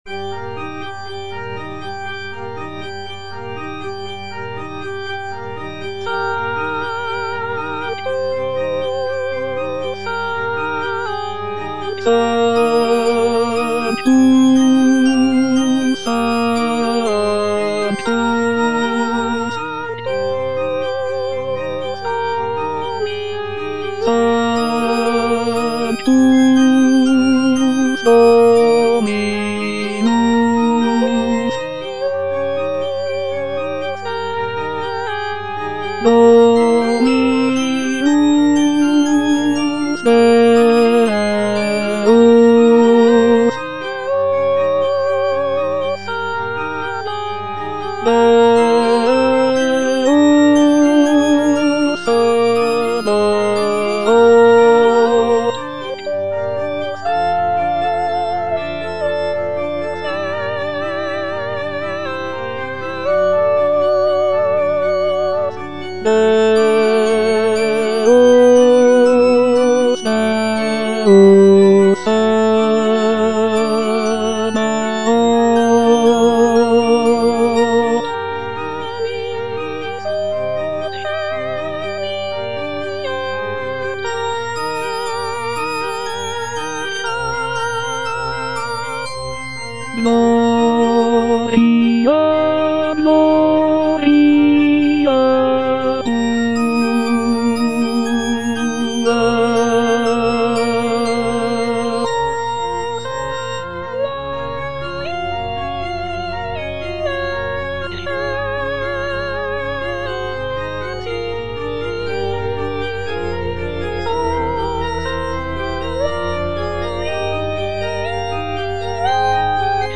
G. FAURÉ - REQUIEM OP.48 (VERSION WITH A SMALLER ORCHESTRA) Sanctus - Bass (Emphasised voice and other voices) Ads stop:
Gabriel Fauré's Requiem op. 48 is a choral-orchestral work that is known for its serene and intimate nature.
This version features a reduced orchestra with only a few instrumental sections, giving the work a more chamber-like quality.